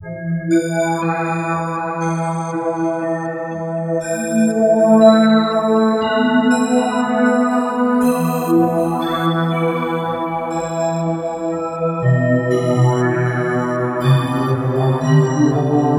Tag: 60 bpm Ambient Loops Synth Loops 1.35 MB wav Key : E